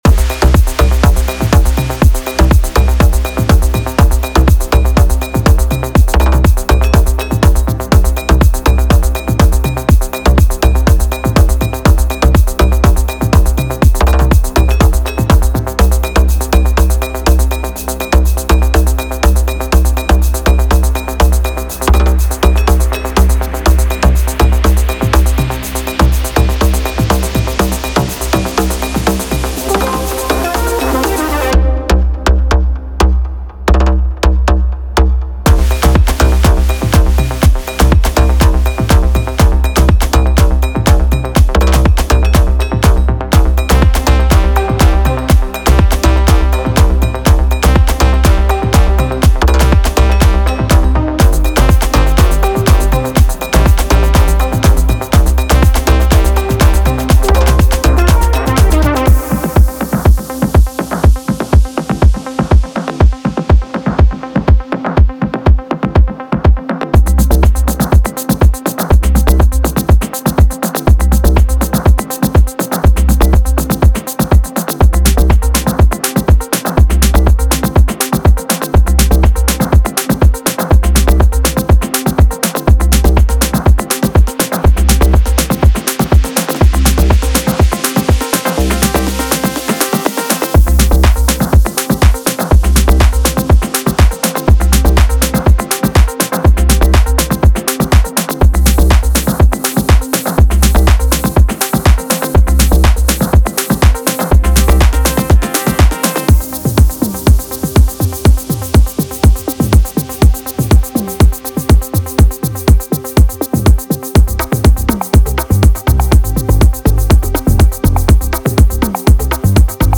Genre:Afro House
丁寧に録音されたアフロパーカッションの豊かなセレクションは、転がるようなグルーヴから鋭く表現力豊かなヒットまで多彩。
21 Bongo Loops
21 Djembe Loops
20 Conga Loops